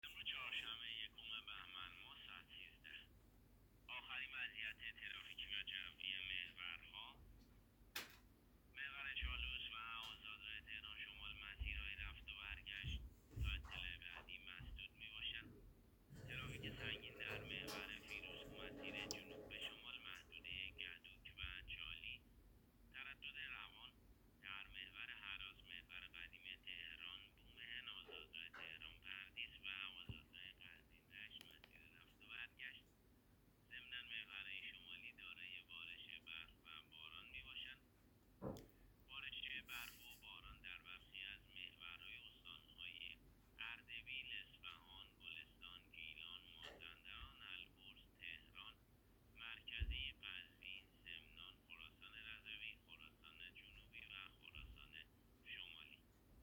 گزارش رادیو اینترنتی از آخرین وضعیت ترافیکی جاده‌ها ساعت ۱۳ اول بهمن؛